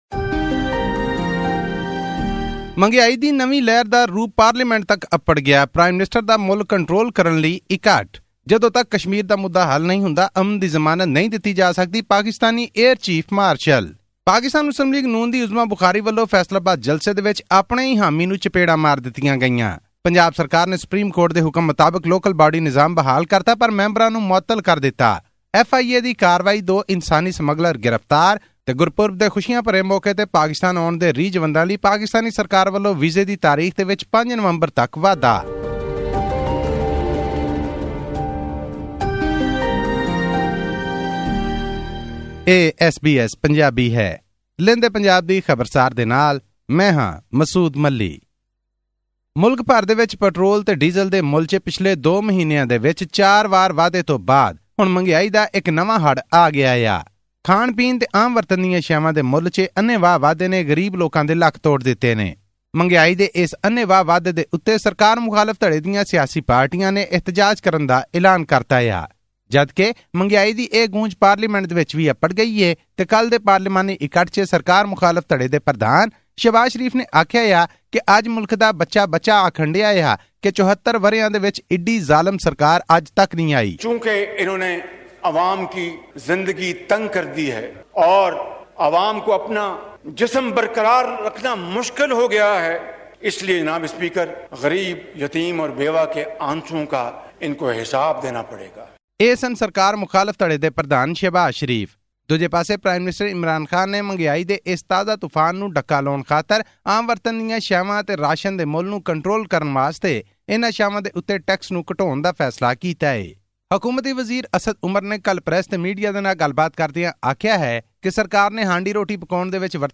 Prime Minister Imran Khan summoned a high-level meeting on 18 October to review the economic situation and discuss measures to tackle skyrocketing inflation amid mounting pressure from the Opposition. This and more in our weekly news bulletin from Pakistan.